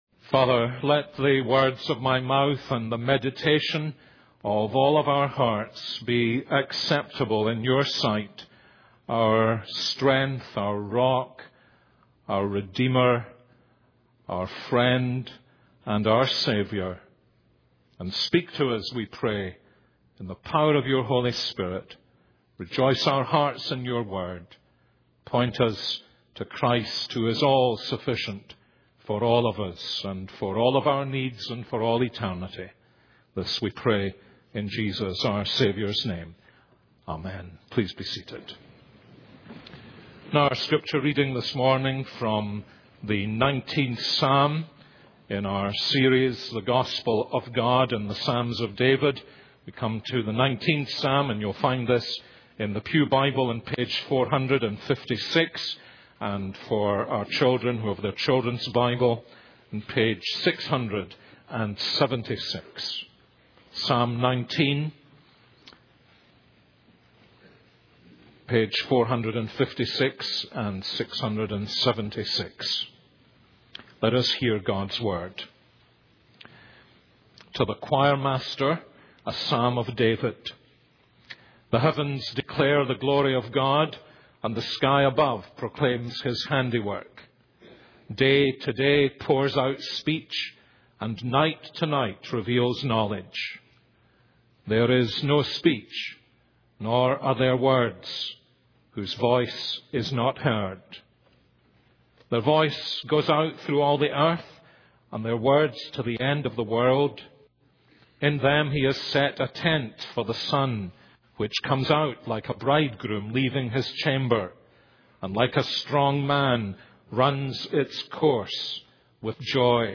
This is a sermon on Psalm 19:1-14.